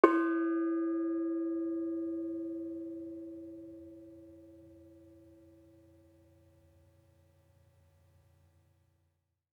Bonang-E3-f.wav